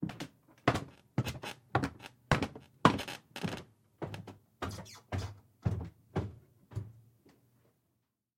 Звуки шагов по лестнице
На этой странице собраны разнообразные звуки шагов по лестнице: от легких шагов на деревянных ступенях до тяжелых шагов по бетону.
Звук спускающегося по лестнице в подвал человека